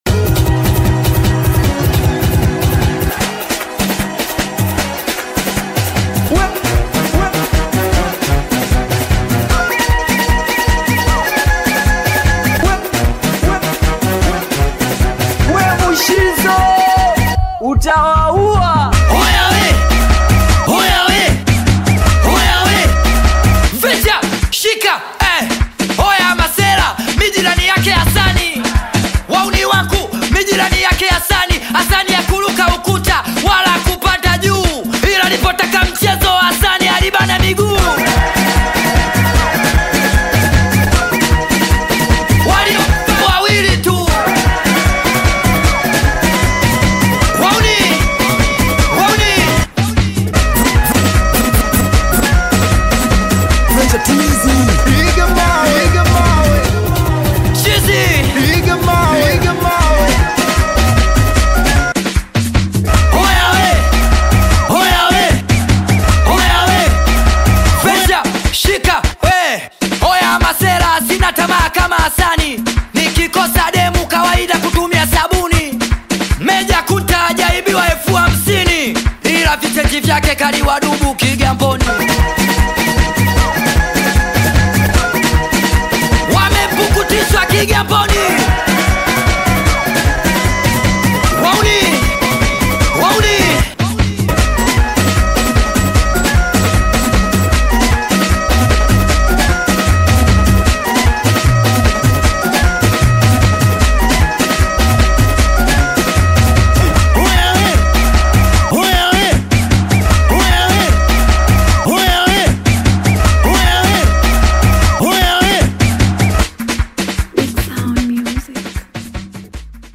Singeli music track
Bongo Flava singeli